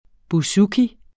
Udtale [ buˈsuki ]